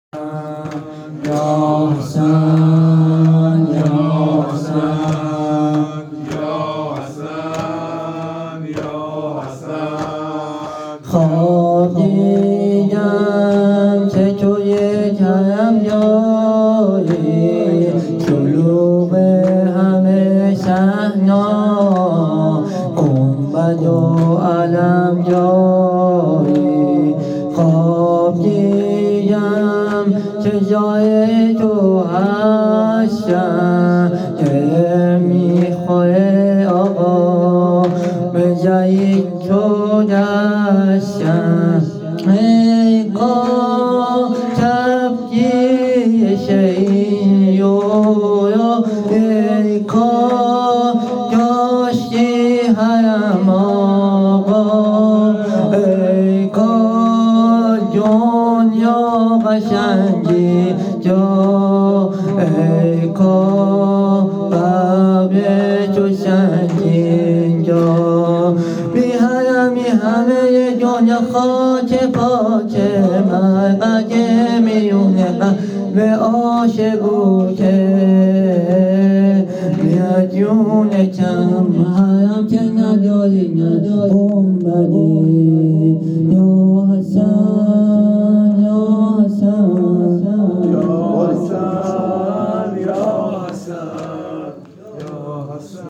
سالروز تخریب قبور ائمه بقیع هیت عشاق العباس تهران
هیت هفتگی عشاق العباس تهران